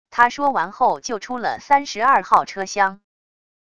他说完后就出了三十二号车厢wav音频生成系统WAV Audio Player